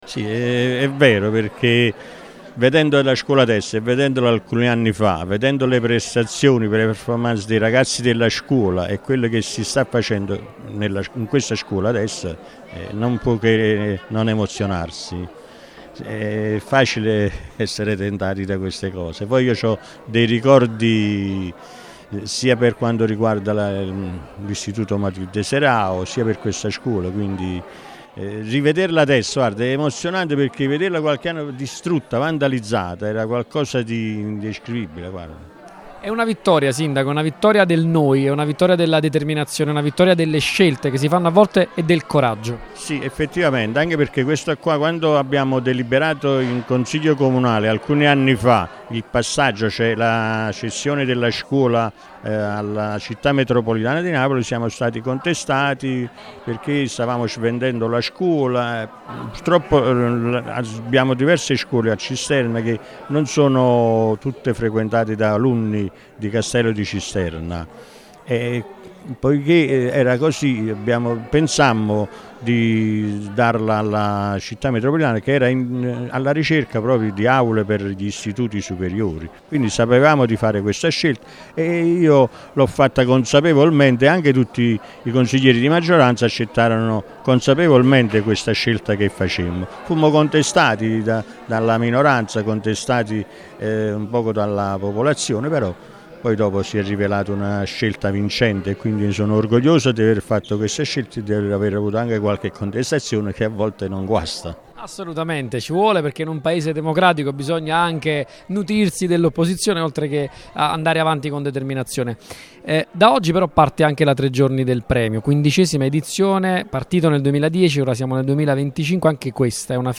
Radio Punto Nuovo, in qualità di radio partner dell’iniziativa, era presente alla presentazione e alla conferenza stampa, e sarà oggi e domani, 24 e 25 ottobre, presente con la regia mobile dall’Auditorium Enrico De Nicola, a partire dalle ore 18:00, per raccontare il convegno odierno e la premiazione dei vincitori.
RIASCOLTA  L’INTERVENTO DEL SINDACO REGA